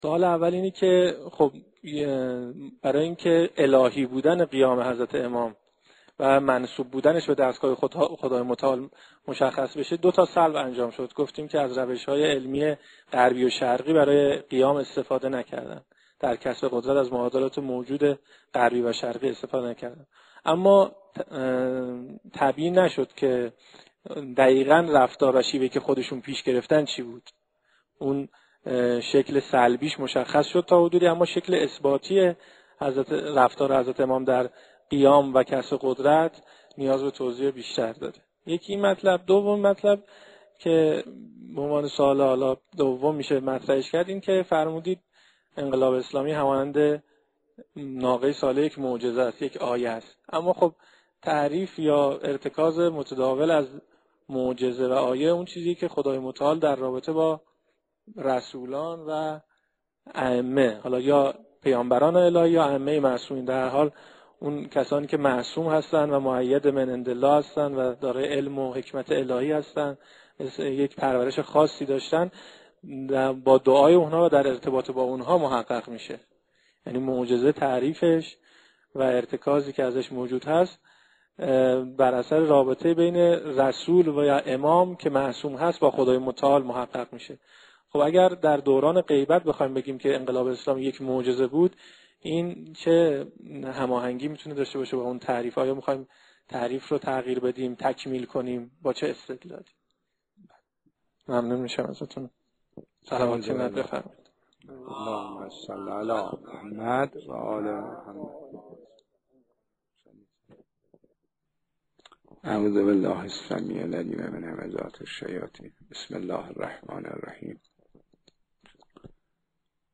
به گزارش رجانیوز، سلسله نشست‌های گفتمان انقلاب اسلامی از آغاز ماه مبارک رمضان، با حضور اقشار مختلف مردم در مصلای قدس شهر مقدس قم برگزار می‌شود.
یکی از ویژگی‌های این سلسله نشست‌ها دوطرفه بودن آن است، به نحوی که حضار در هر جای بحث می‌توانند نسبت به مسائل مطروحه نظر خود را ارائه کنند.